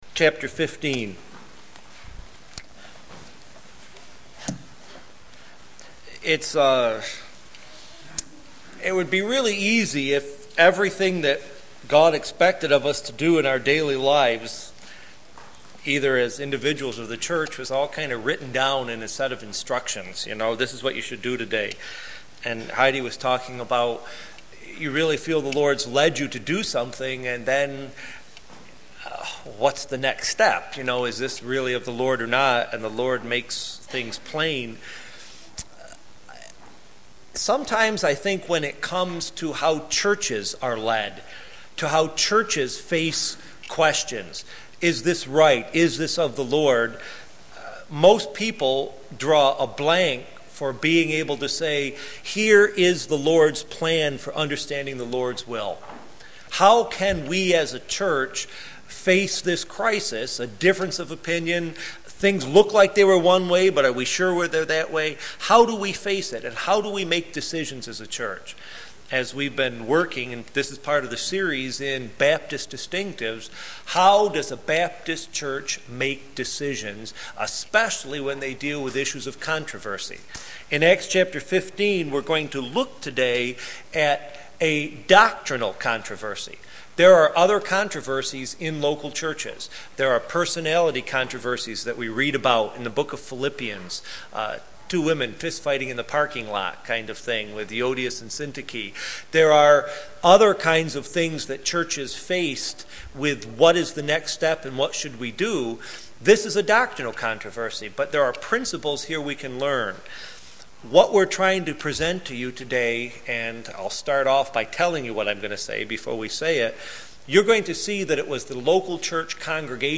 How Does a Baptist Make Decisions – Sermons